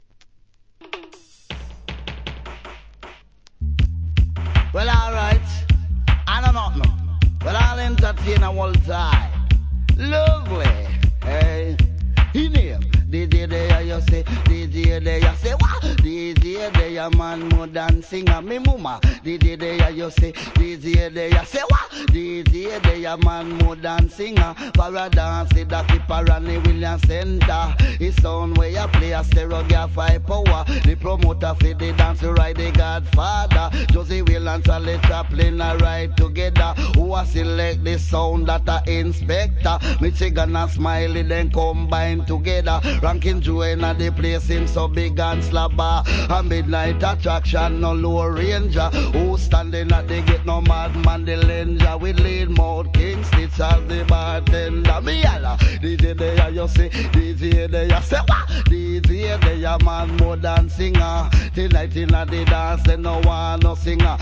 (A-1でやや周期的なノイズ)
REGGAE